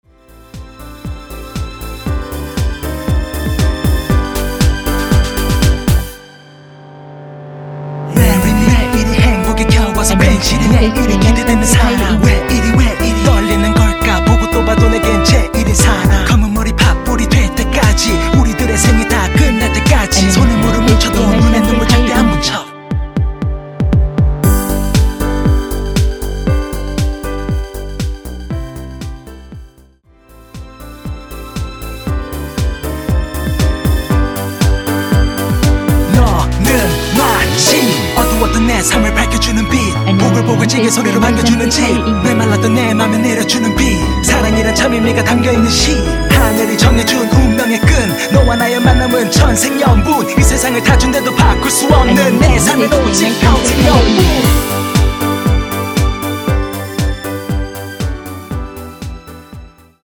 전주가 없는 곡이라 2마디 전주 만들어 놓았습니다.(원키 멜로디MR 미리듣기 참조)
원키에서(+5)올린 랩 포함된 MR입니다.(미리듣긱 확인)
앞부분30초, 뒷부분30초씩 편집해서 올려 드리고 있습니다.
중간에 음이 끈어지고 다시 나오는 이유는